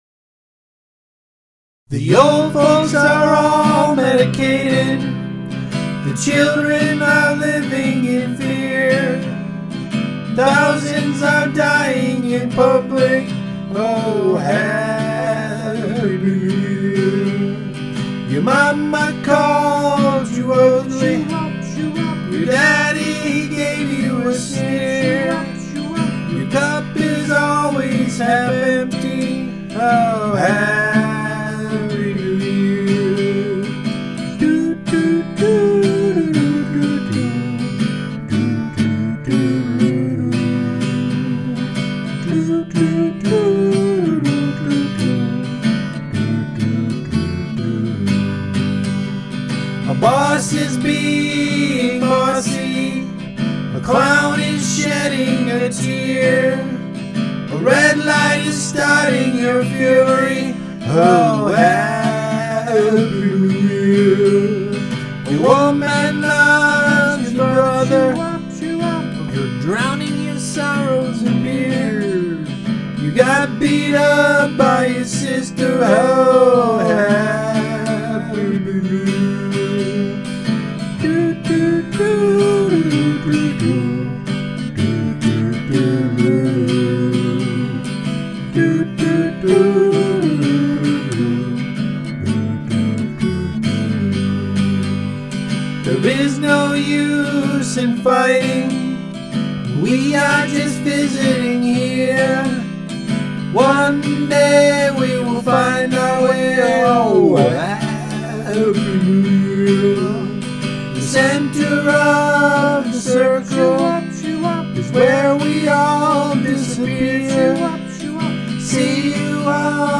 jazz, classical, rock